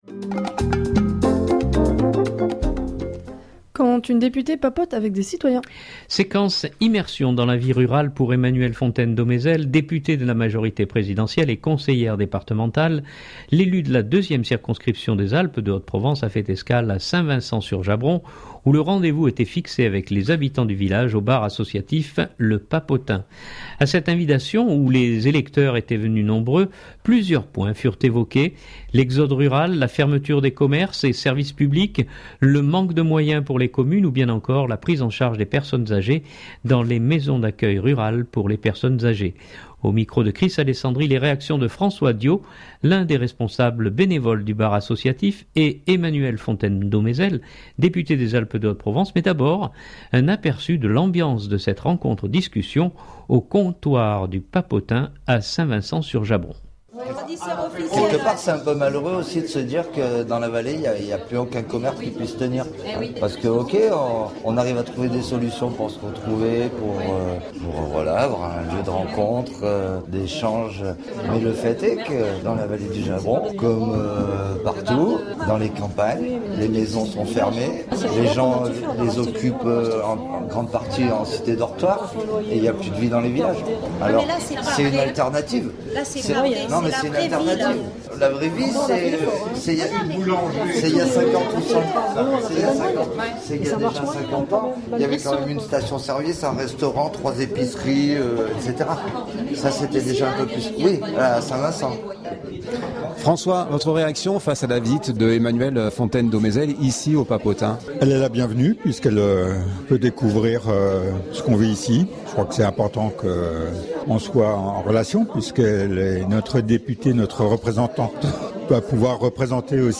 Mais d’abord un aperçu de l’ambiance de cette rencontre-discussion, au comptoir du « Papotin » à Saint Vincent sur Jabron…